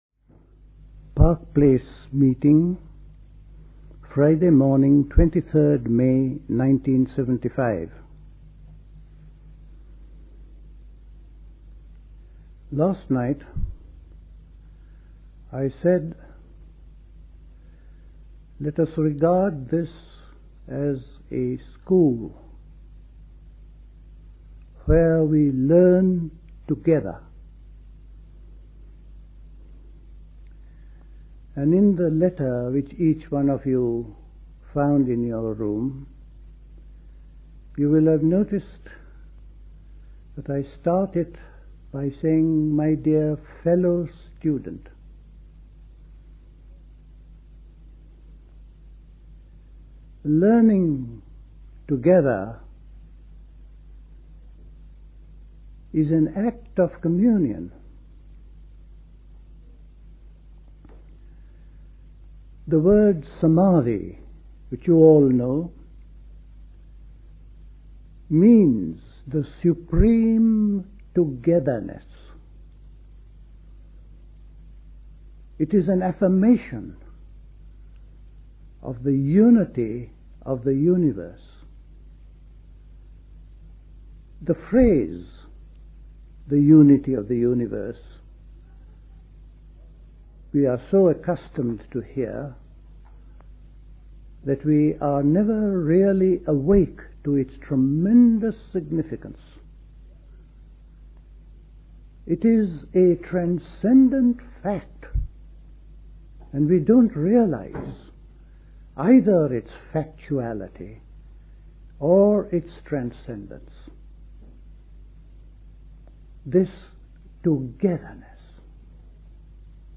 Recorded at the 1975 Park Place Summer School.